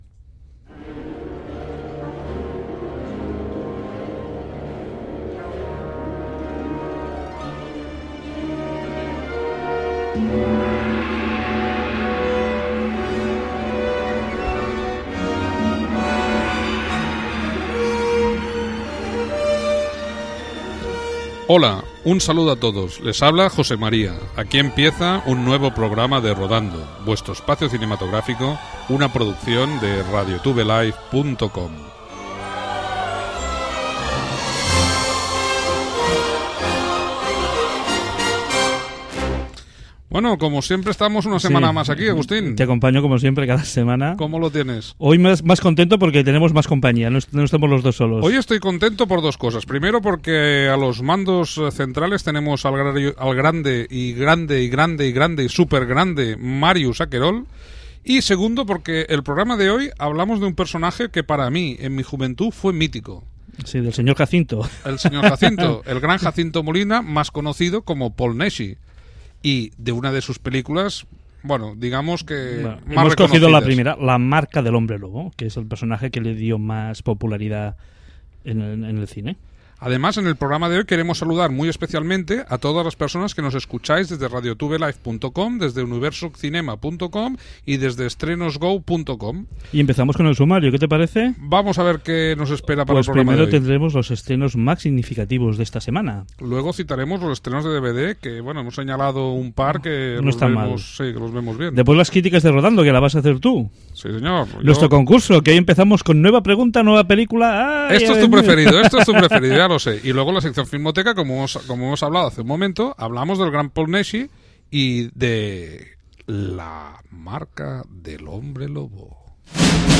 Cada semana puedes escuchar el programa radiofónico "Rodando"